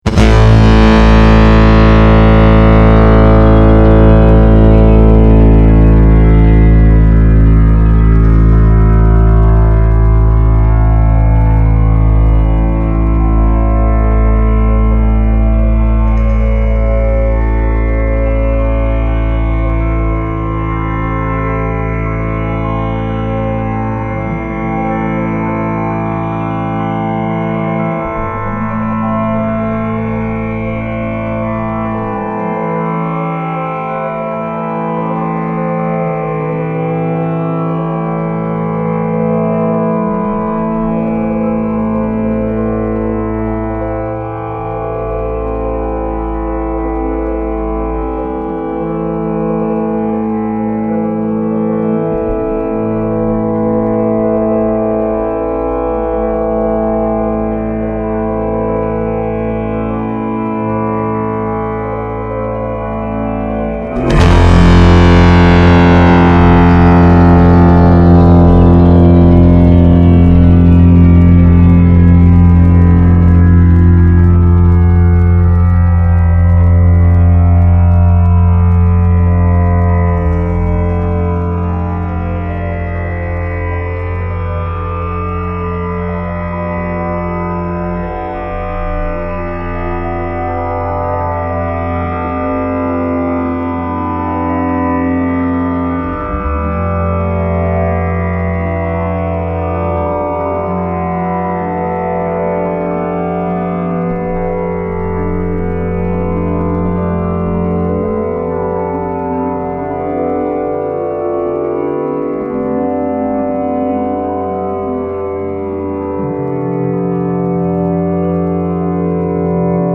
Style Style Ambient, Soundtrack
Mood Mood Dark, Intense, Mysterious
Featured Featured Synth